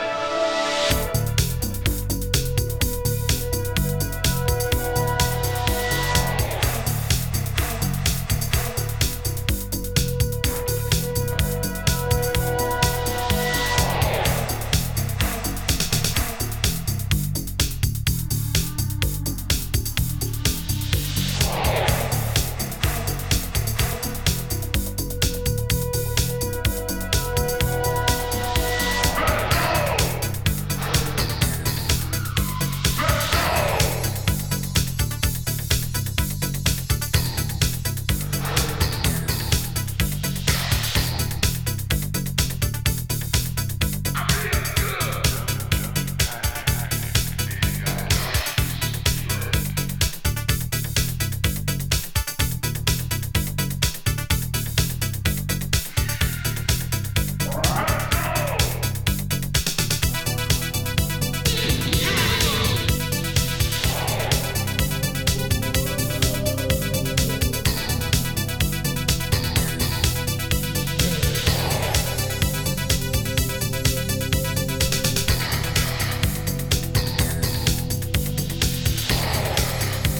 ロックとハウスが自然に混ざりあった